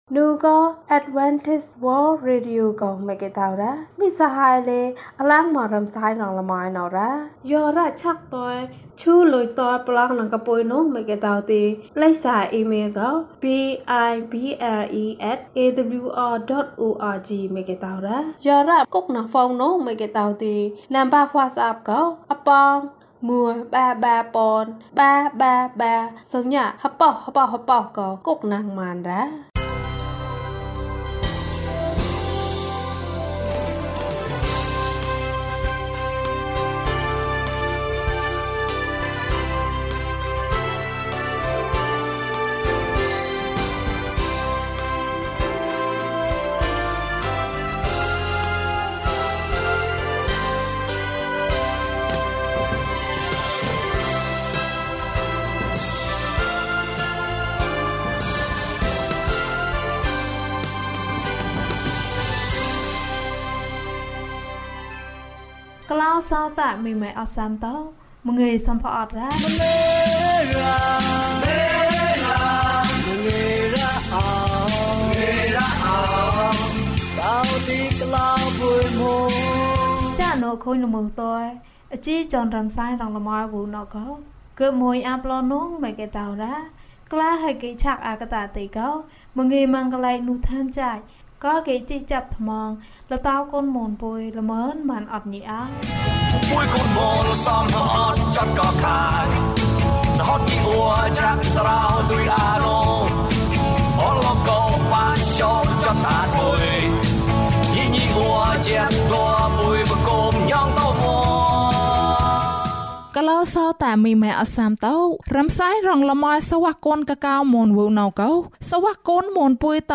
ညံၚ်နဲဘဝယေသှုခရေတ်၀၃။ ကျန်းမာခြင်းအကြောင်းအရာ။ ဓမ္မသီချင်း။ တရားဒေသနာ။